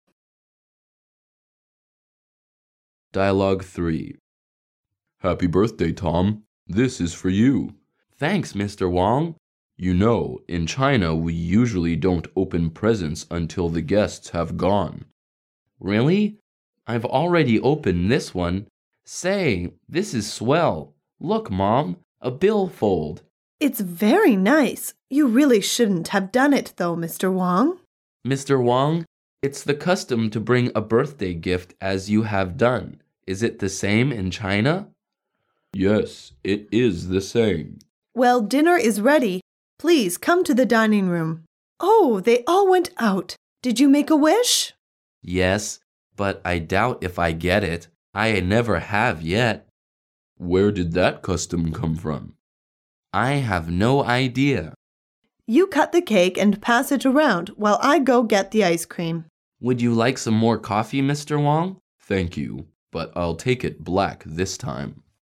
Dialoug 3